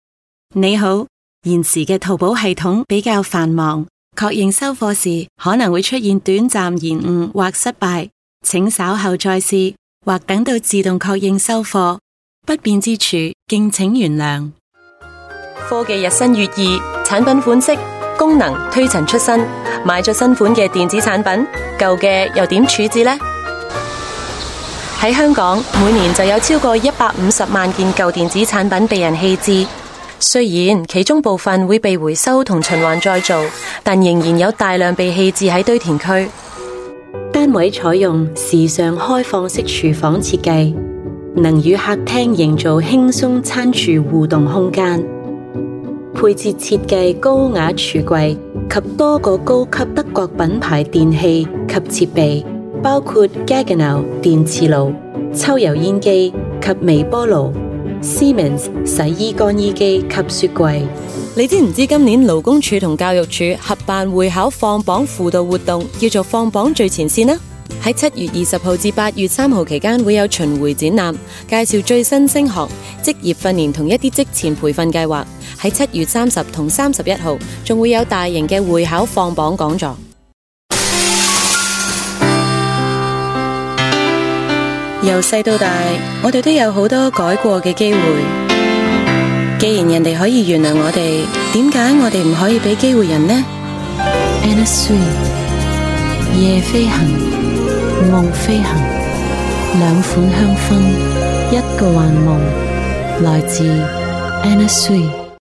Voice Samples: Cantonese Voice
female